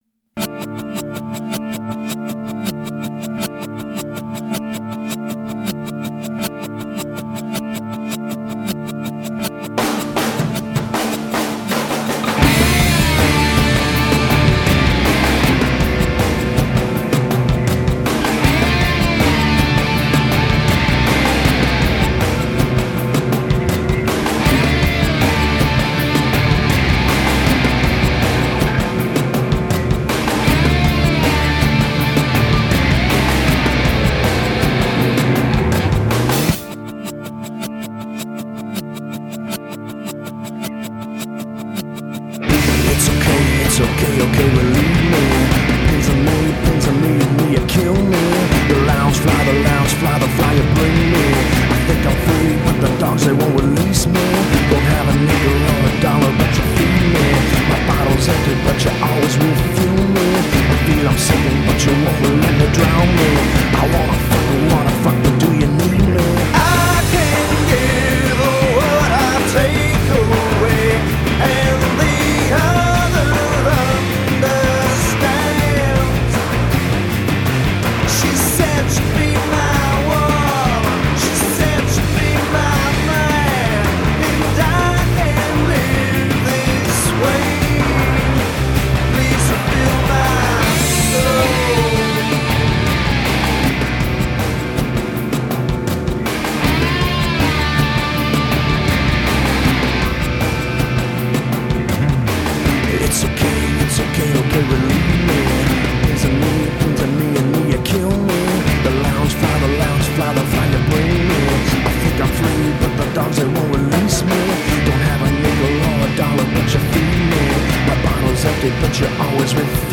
融入爵士、藍調、放克等不同風格